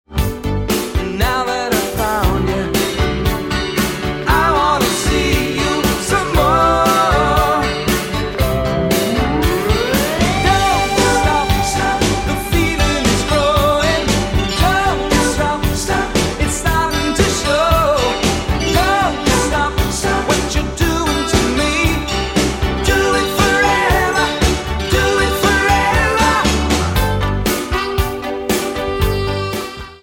guitar, keyboards, percussion, vocals
bass
drums